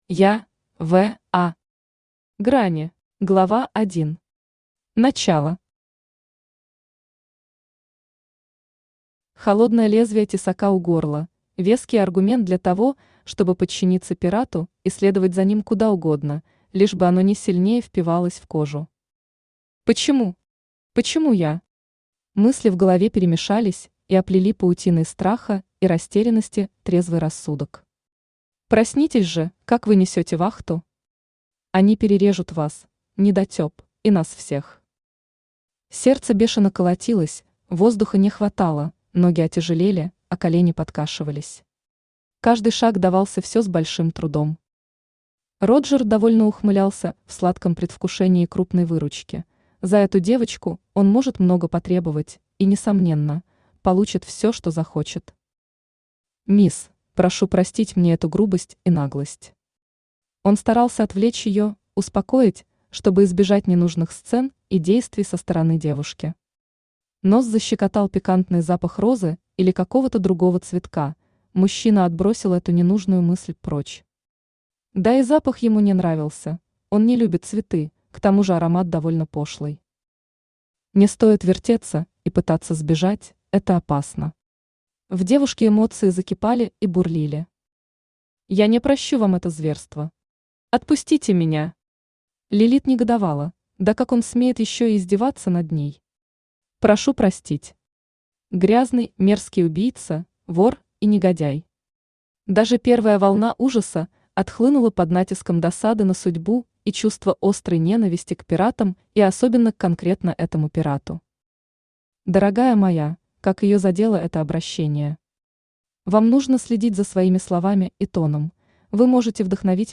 Аудиокнига Грани | Библиотека аудиокниг
Aудиокнига Грани Автор Я.В.А. Читает аудиокнигу Авточтец ЛитРес.